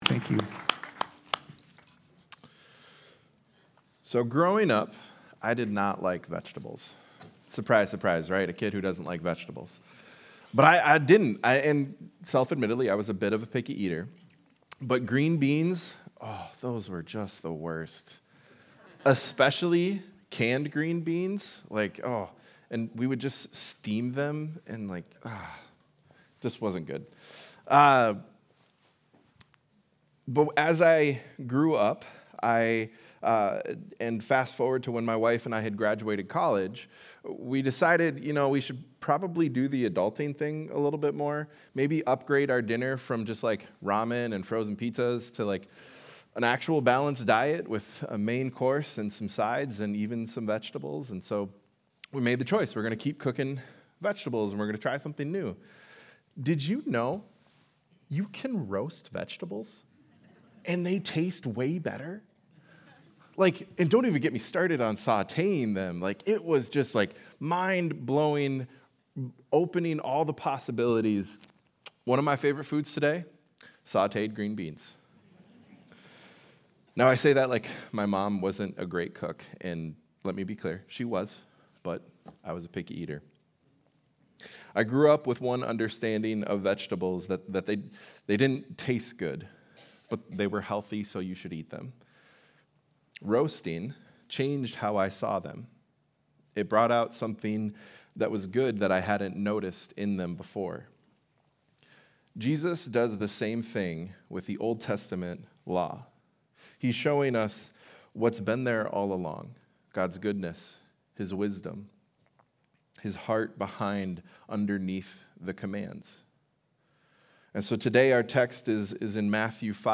Sunday Sermon: 8-3-25